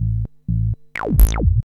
5208L B-LOOP.wav